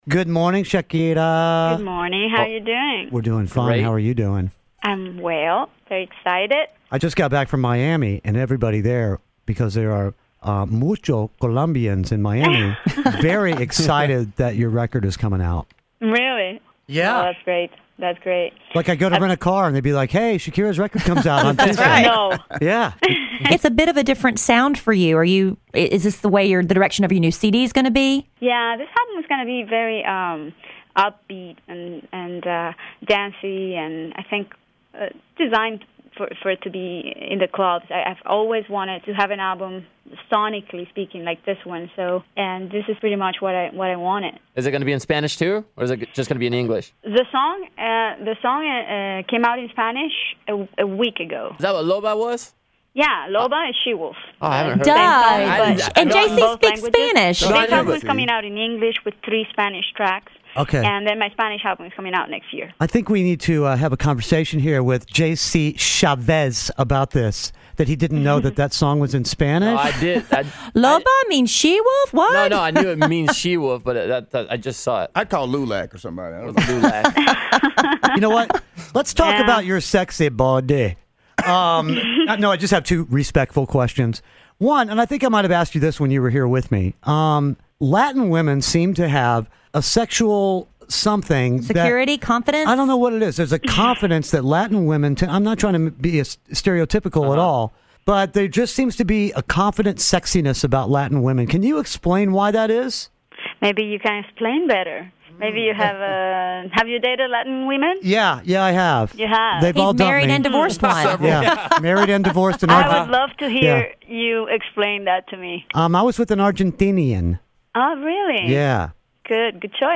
Shakira Interview
Kidd Kraddick in the Morning interviews Shakira!